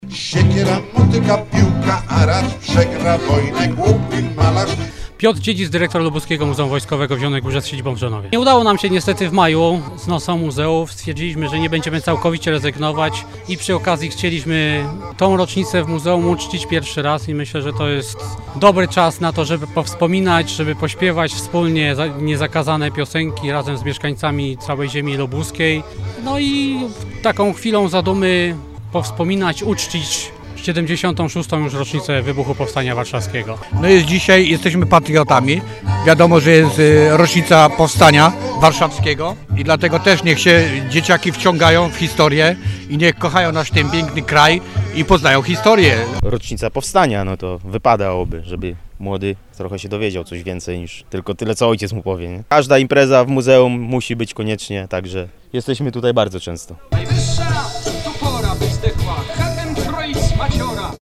W muzeum w Drzonowie wspólnie śpiewano (nie)zakazane piosenki
Szczególną atrakcją wydarzenia było wspólne śpiewanie piosenek, które powstały w trakcie powstania.